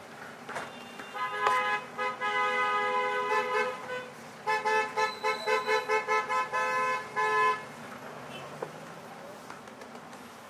描述：汽车喇叭从近距离
标签： 按喇叭 汽车喇叭 汽车
声道立体声